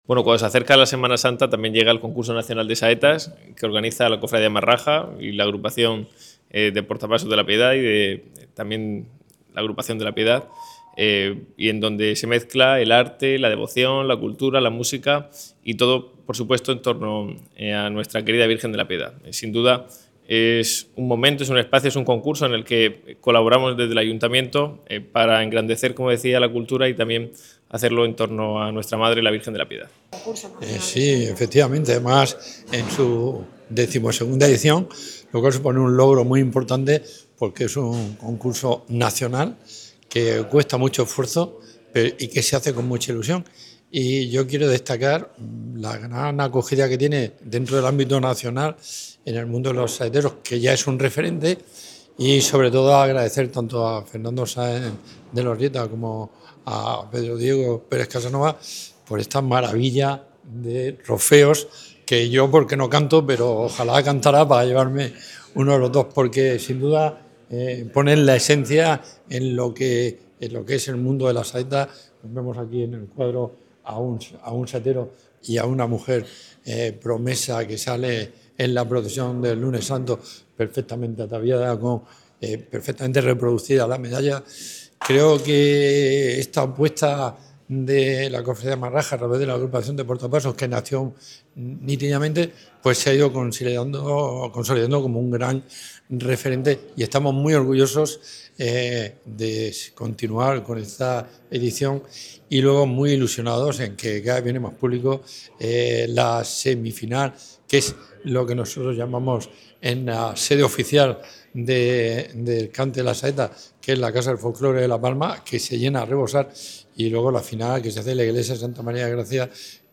Presentación del XII edición del Concurso Nacional de Saetas